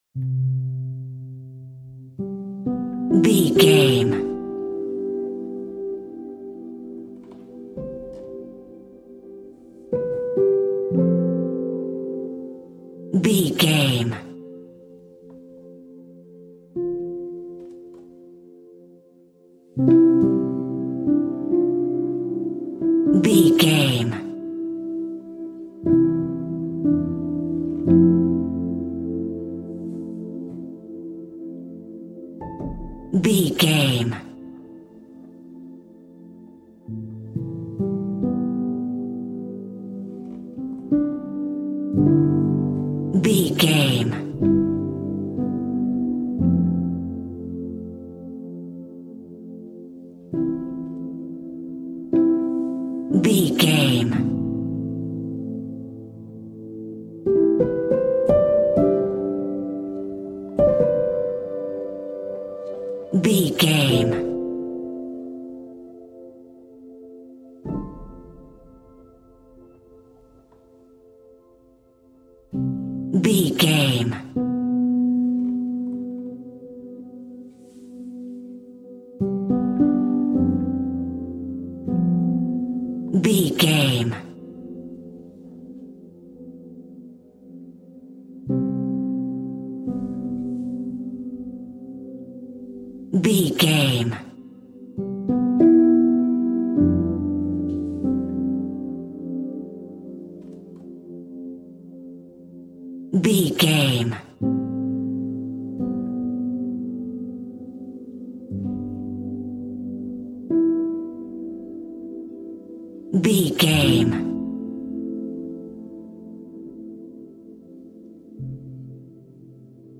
Ionian/Major
Slow
relaxed
tranquil
synthesiser
drum machine